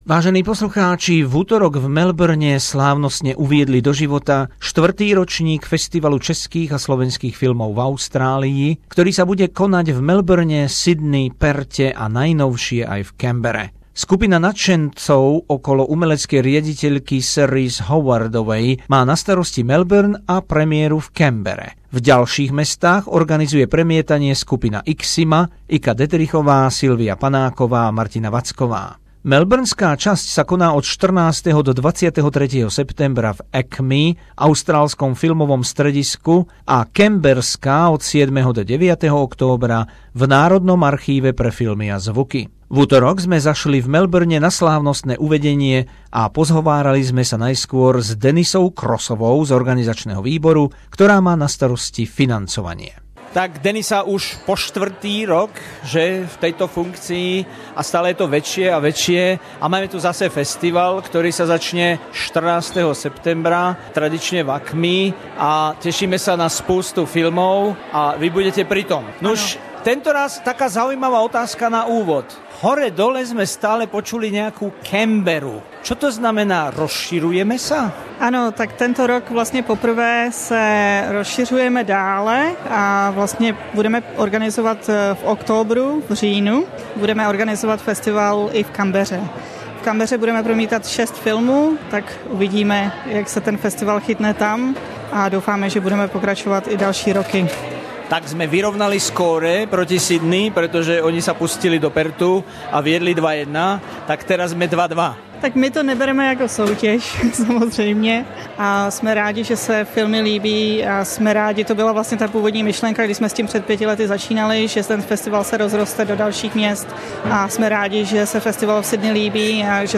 Reportáž zo slávnostného uvedenia 4. festivalu českých a slovenských filmov v Austrálii v Melbourne